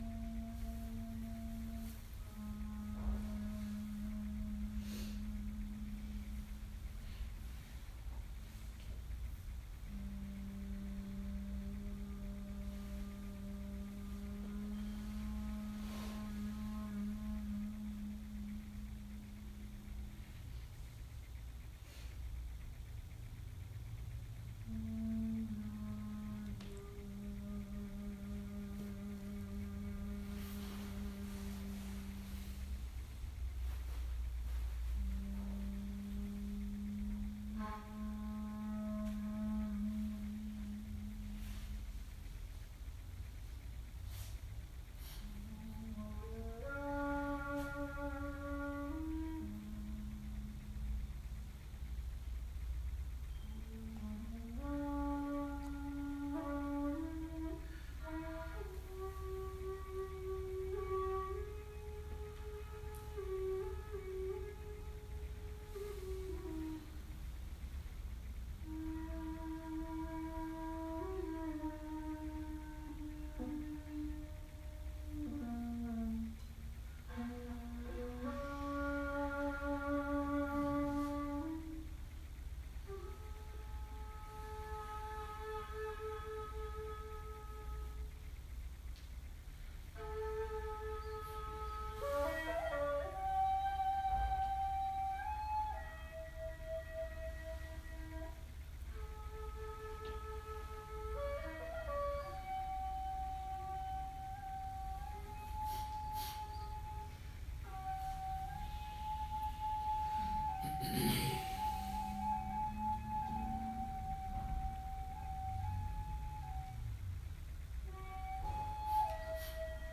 The Wheel Maker: Dharma Talk
December 8 2018 Southern Palm Zen Group